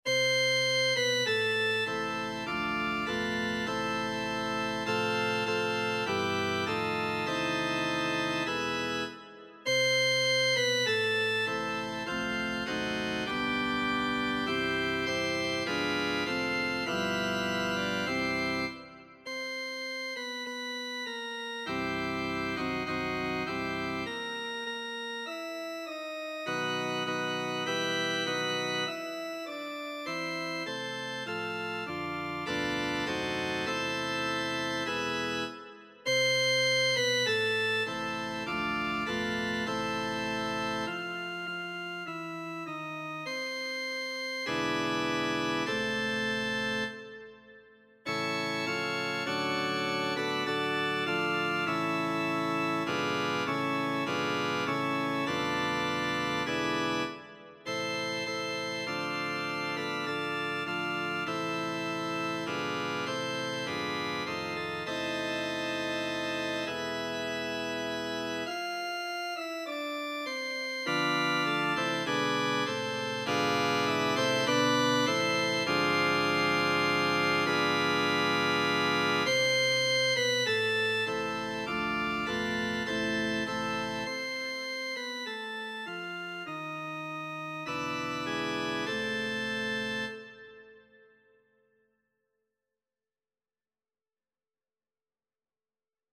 Alle Stimmen